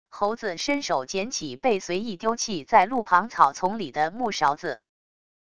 猴子伸手捡起被随意丢弃在路旁草丛里的木勺子wav音频